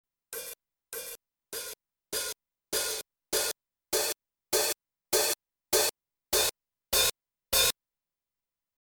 02 はペダルHi-hat でオープンの音をミュートしてます。
どうでしょうか？ 音色の変わり目で若干不自然な部分もありますが、
HiHat_02.mp3